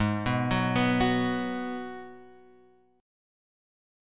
G# Minor 7th